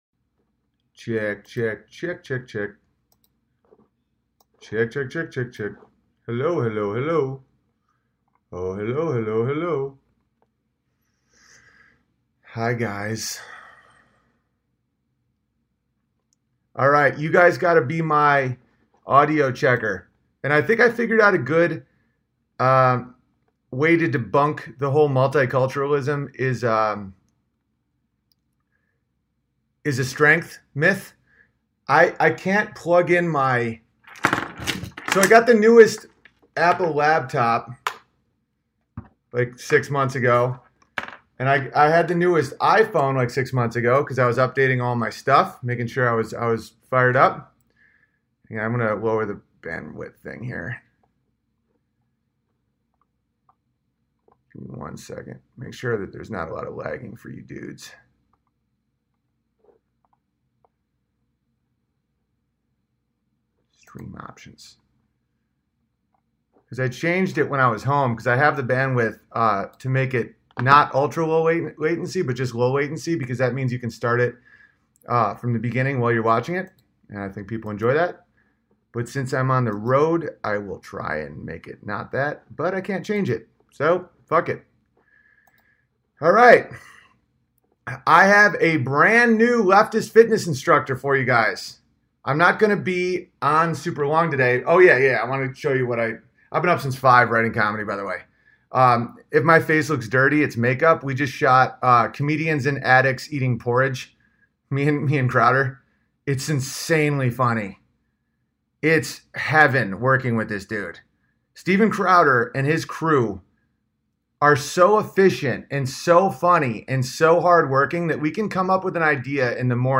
Live stream from the road just before the Persona Non Grata tour kicks off. Cover the Norm situation, debut Leftist Fitness Instructor Vol. 2.